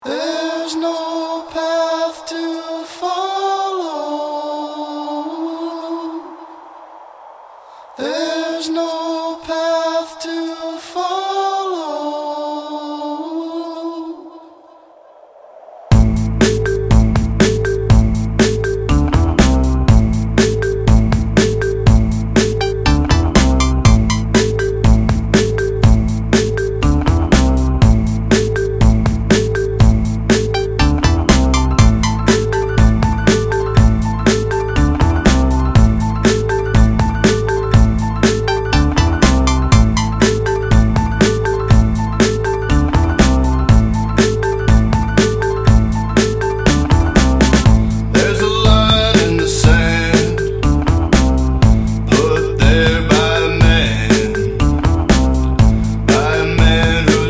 Жанр:Electronic